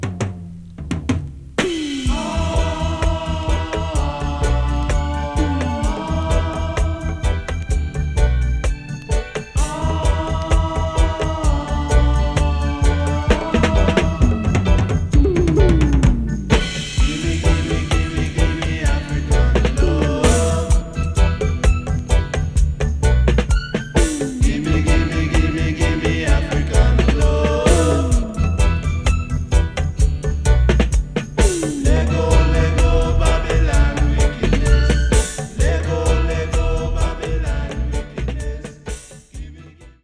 Genre: Reggae, Roots